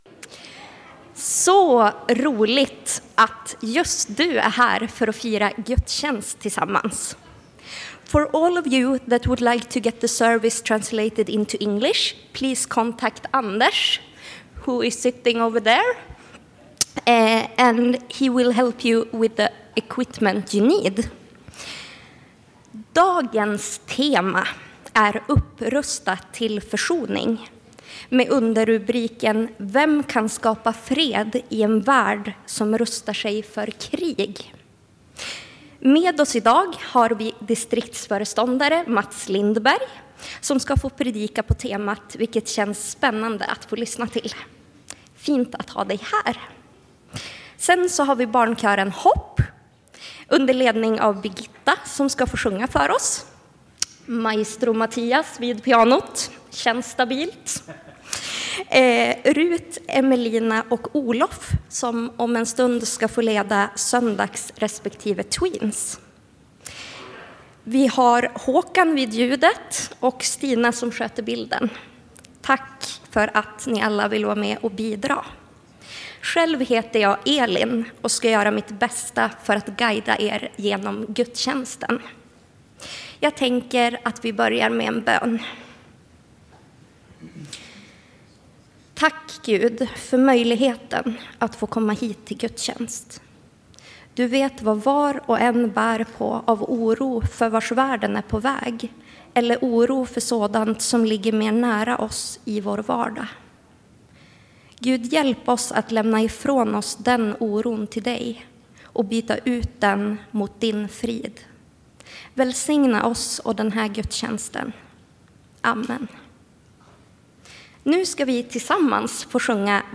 Lyssna till gudstjänsten! Barnkören Hopp sjöng också några fina och hoppingivande sånger.